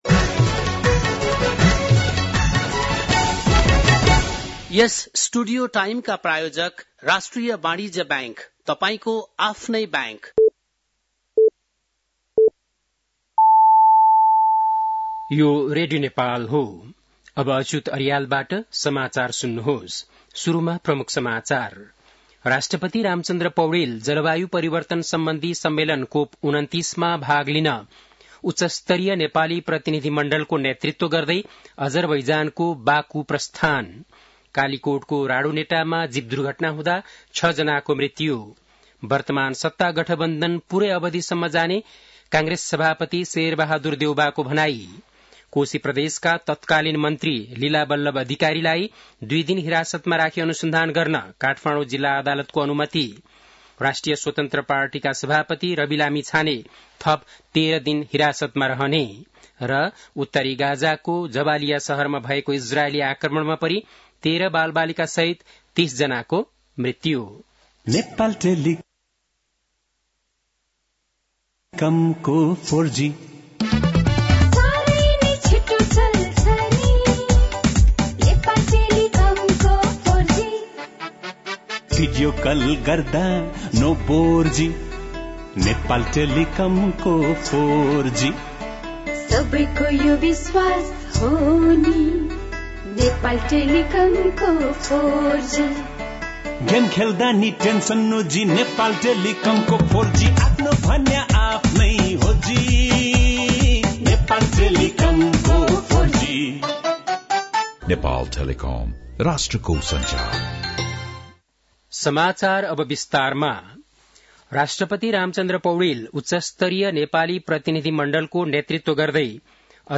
बेलुकी ७ बजेको नेपाली समाचार : २६ कार्तिक , २०८१
7-pm-news-1.mp3